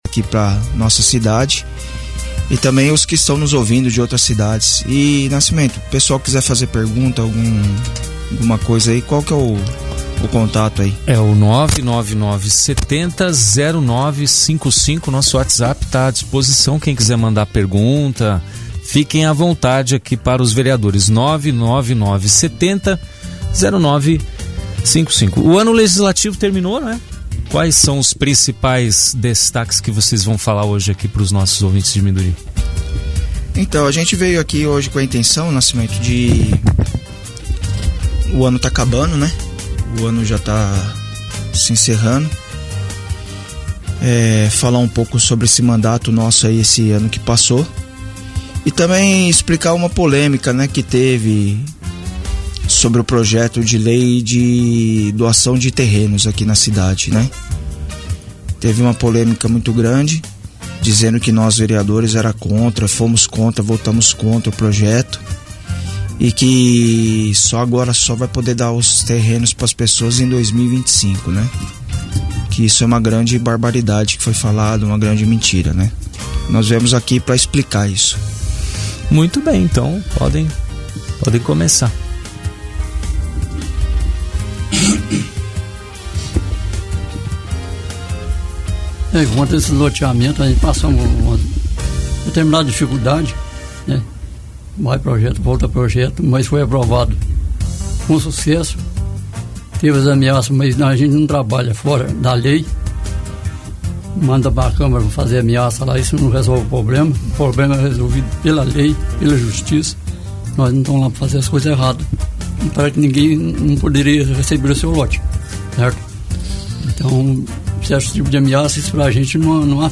Recebemos hoje, os vereadores: Dilermando (Presidente da Câmara), Vilson (Vice-Presidente), Peterson (Secretário) e Rildo Garcia.